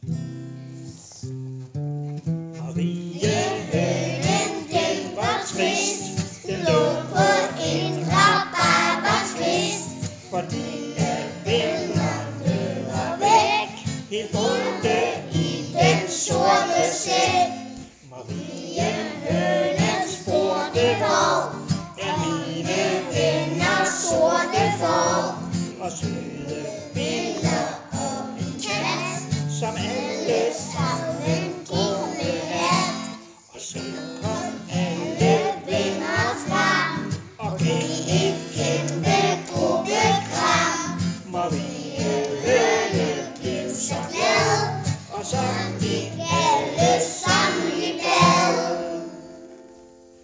Hør "Mariehønen den var trist" med Børnetjenesten i Messiaskirken.
Sangen er på melodien "Marienhønen evigglad" og uden brug af organist eller ekstern musiker.